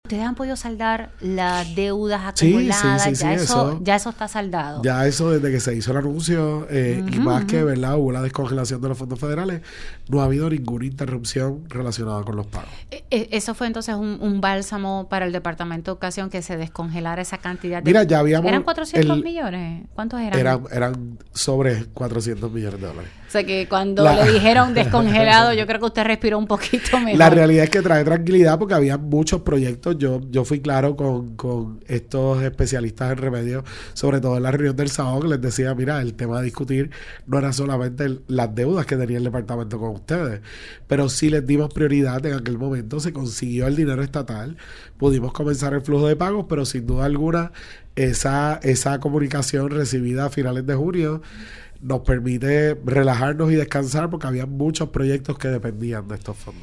El secretario de Educación, Eliezer Ramos, ofreció en RADIO ISLA 1320 una actualización ante el inicio del año académico 2025- 2026.